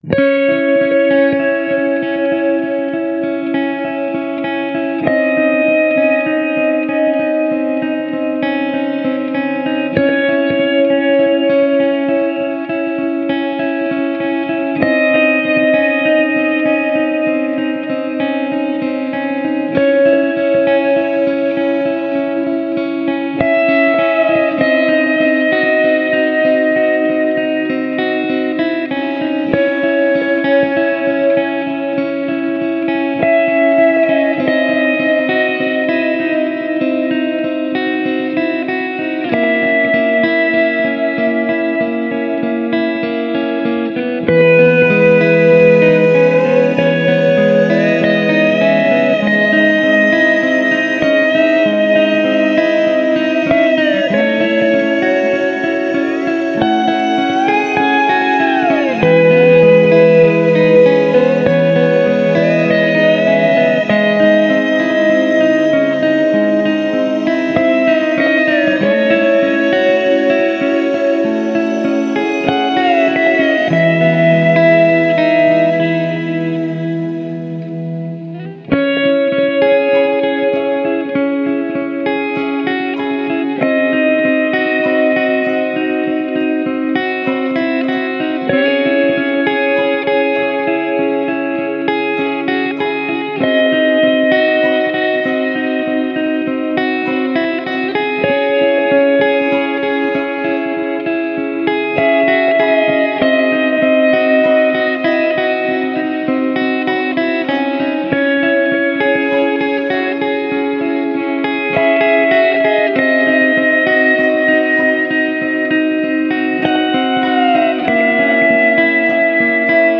Sustained six string mutations.
Warm longing hopeful guitar theme.